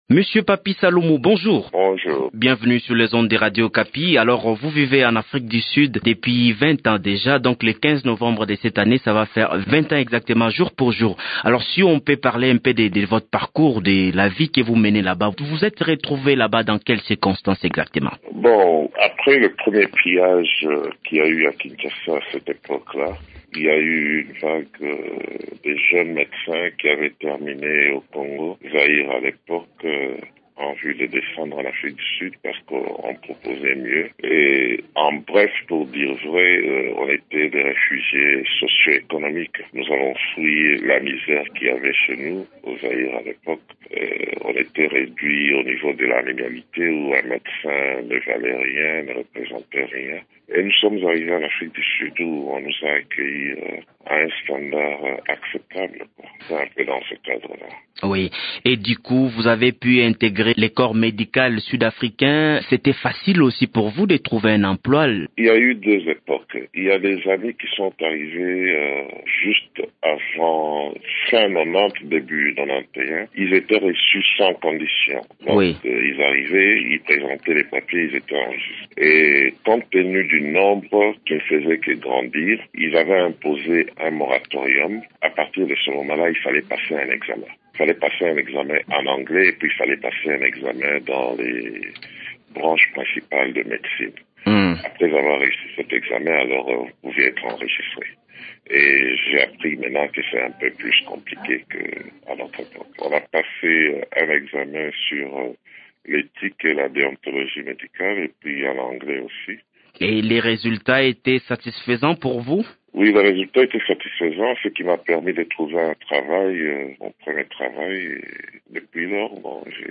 dit-il dans cet entretien téléphonique